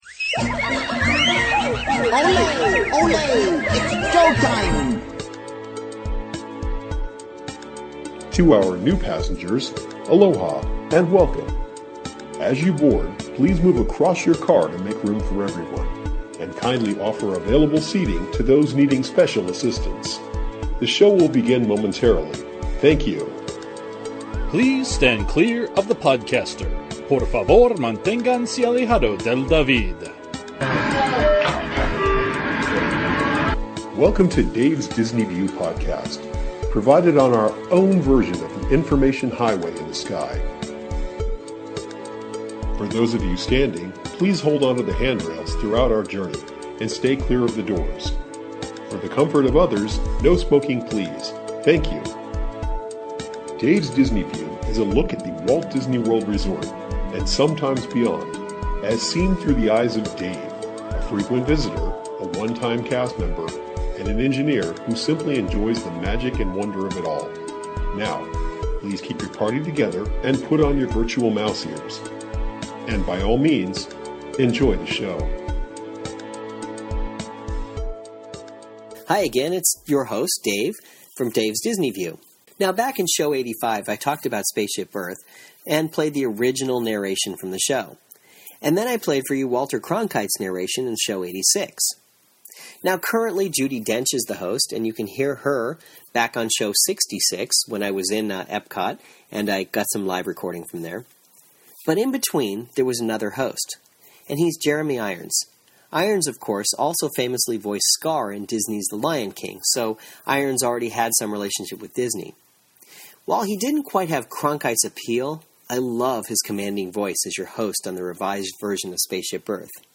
Here is the Irons version - considered by many to be the voice of the attraction.